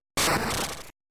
File File history File usage Metadata PokémonTCG_Unused_SFX_09.ogg  (Ogg Vorbis sound file, length 1.1 s, 323 kbps) This file is an audio rip from a(n) Game Boy Color game.
PokémonTCG_Unused_SFX_09.ogg.mp3